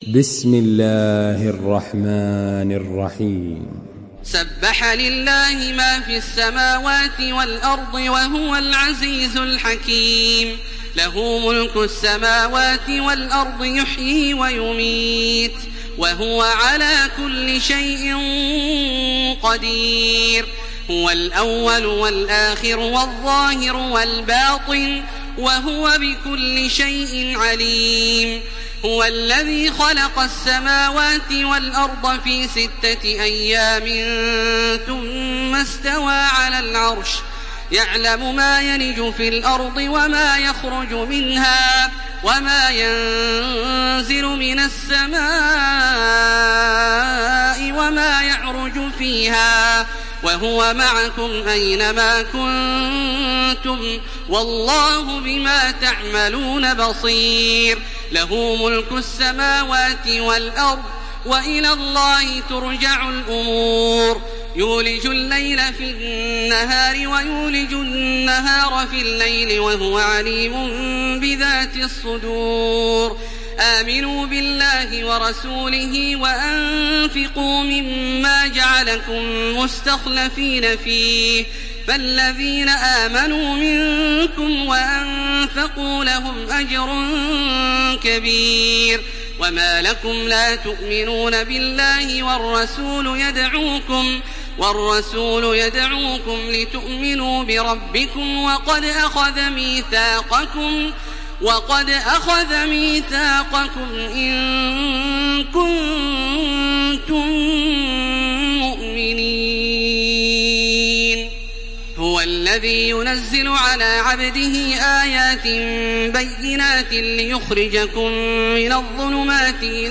Makkah Taraweeh 1429
Murattal